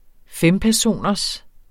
Udtale [ ˈfεmpæɐ̯ˌsoˀnʌs ]